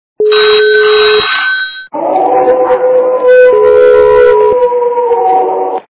» Звуки » Смешные » Звонок - Страшный звонок
При прослушивании Звонок - Страшный звонок качество понижено и присутствуют гудки.
Звук Звонок - Страшный звонок